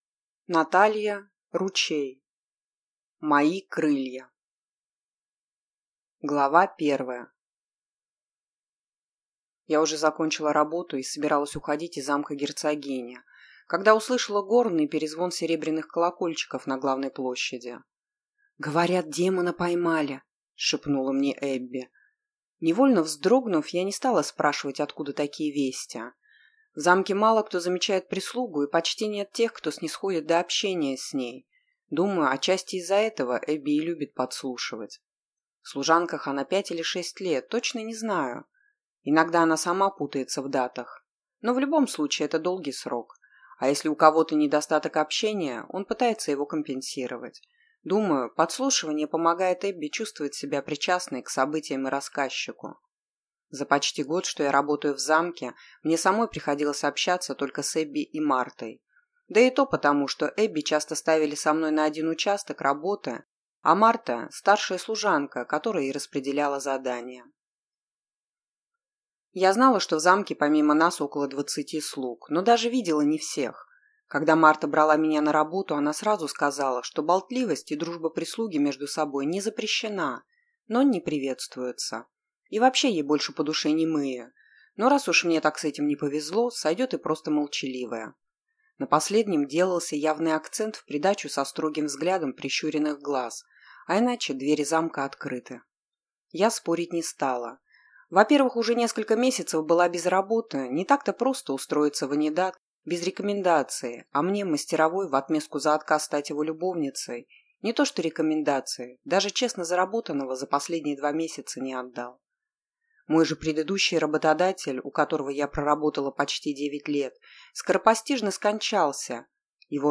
Аудиокнига Мои крылья | Библиотека аудиокниг
Прослушать и бесплатно скачать фрагмент аудиокниги